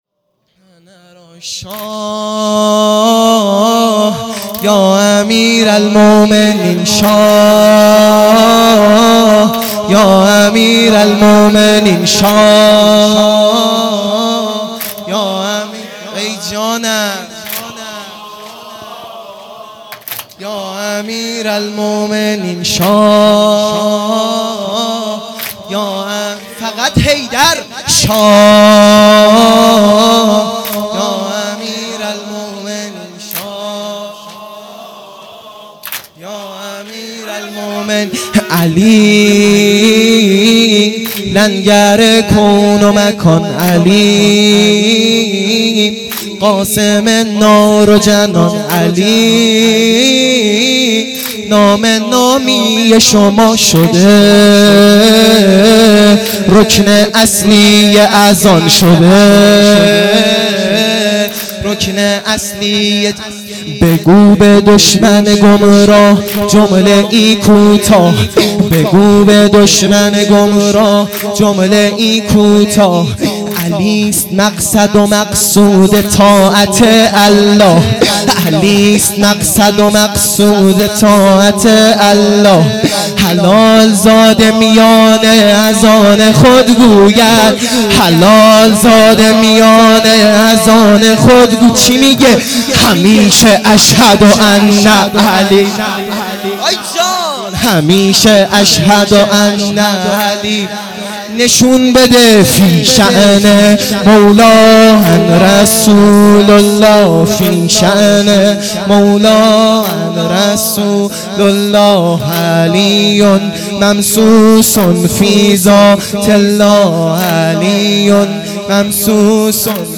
وفات حضرت خدیجه سلام الله